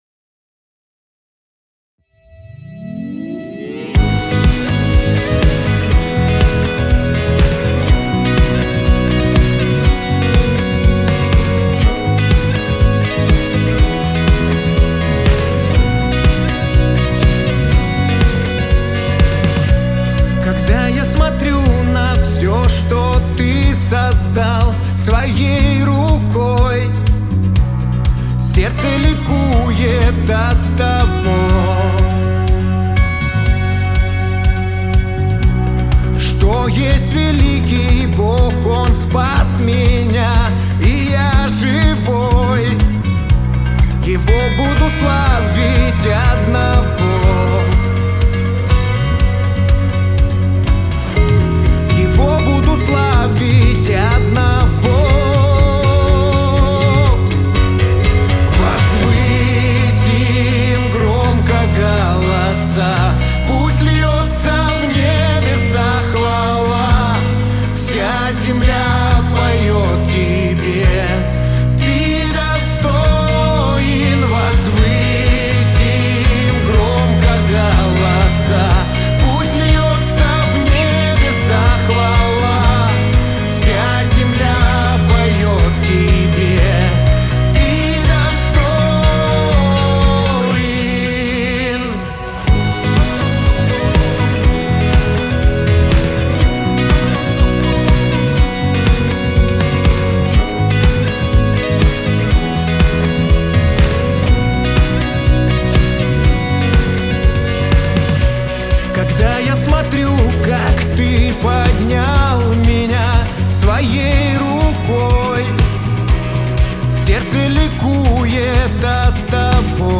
8891 просмотр 7685 прослушиваний 664 скачивания BPM: 122